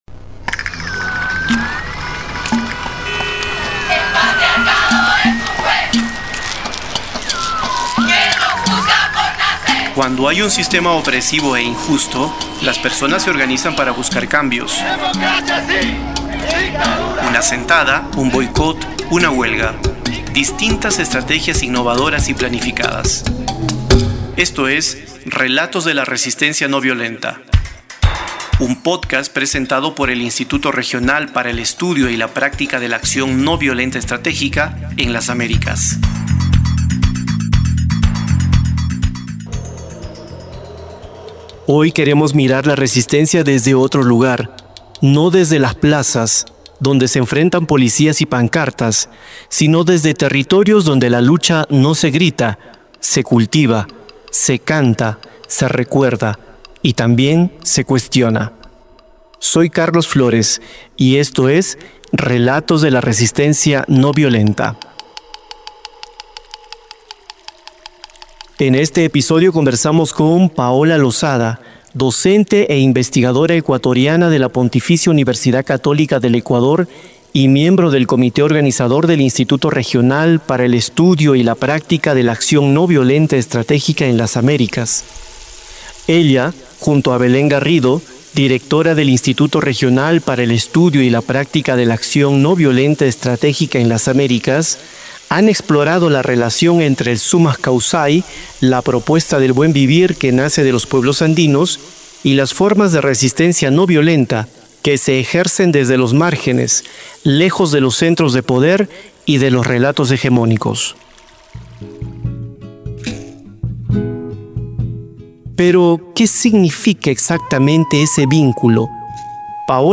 Entrevista - Acción Noviolenta